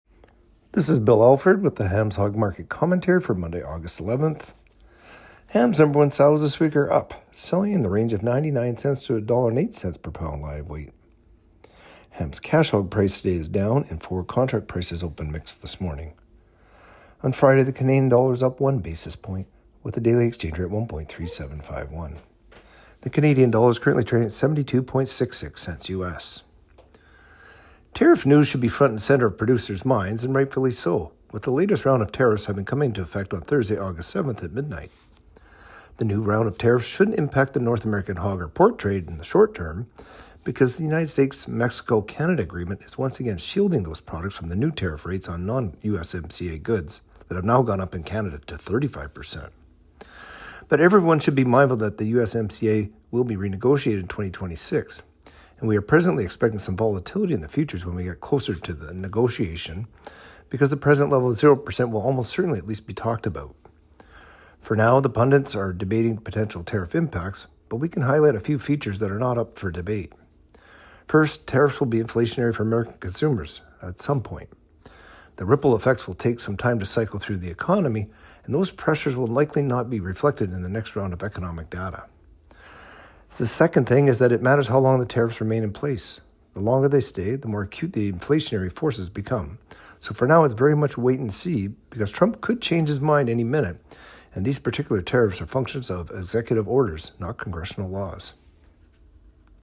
Hog-Market-Commentary-Aug.-11-25.mp3